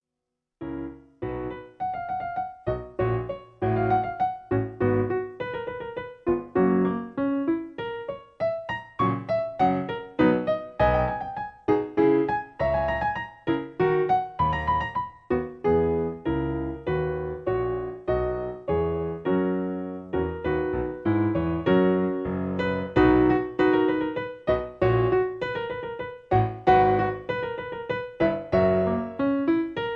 In G sharp. Piano Accompaniment